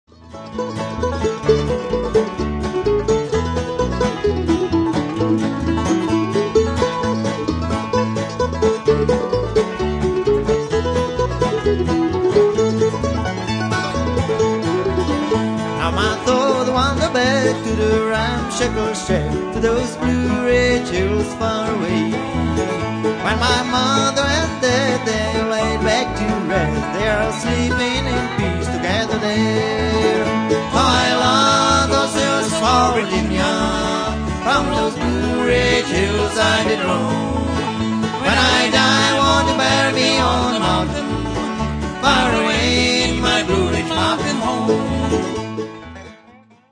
guitar, vocal
banjo
dobro
mandolin
el.bass